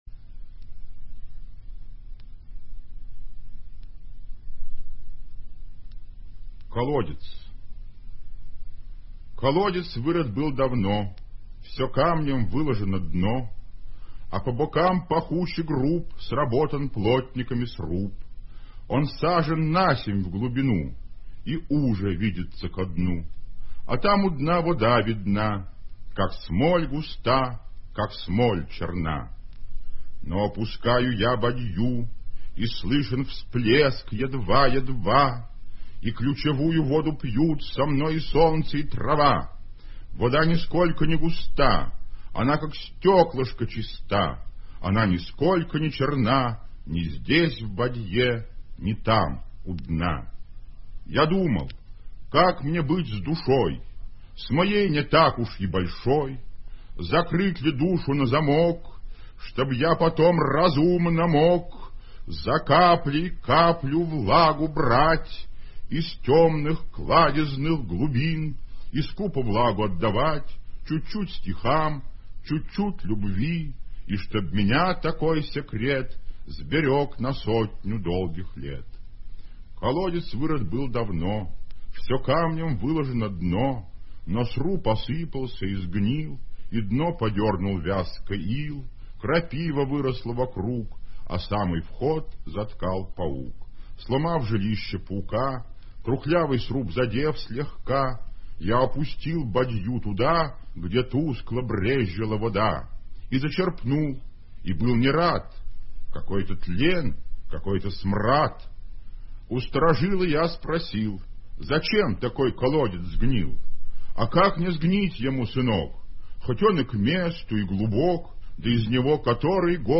vladimir-solouhin-kolodets-chitaet-avtor